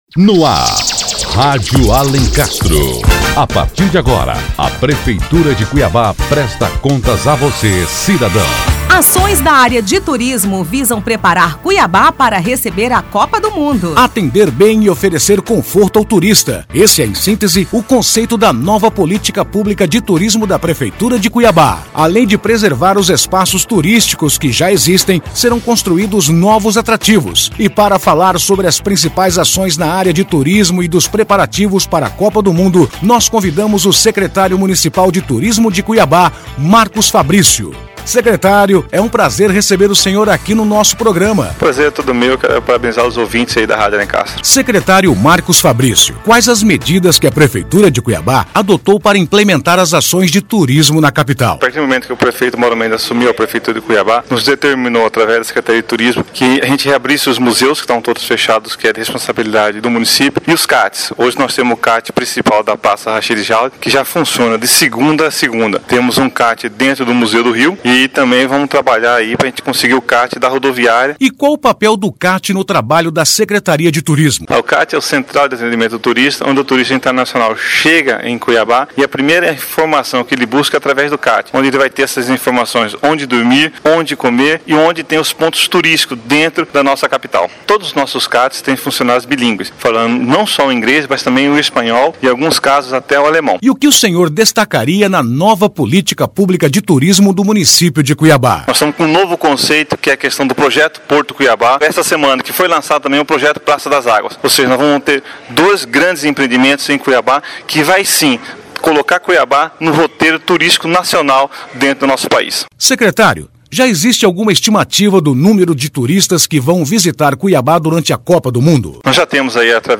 O Secretário Municipal de Turismo, Marcus Fabrício, fala sobre as ações da secretaria que visam preparar Cuiabá...